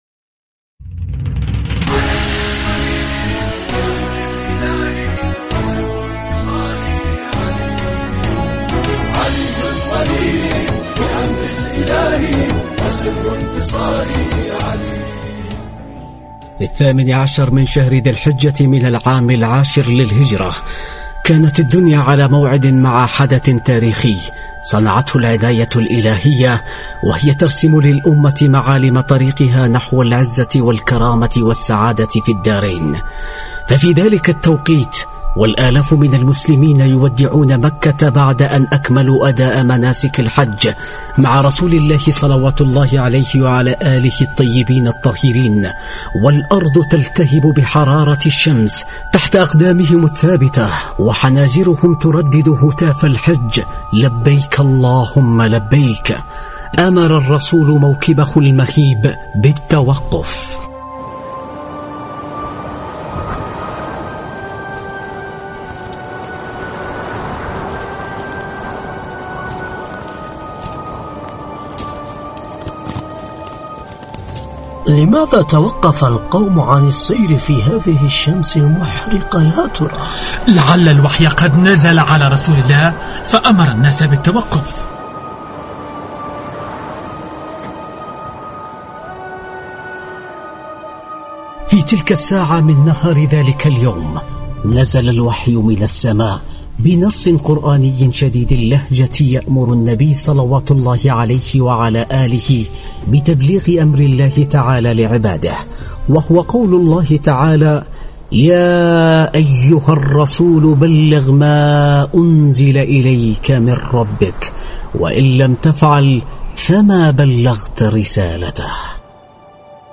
دراما عن يوم الولاية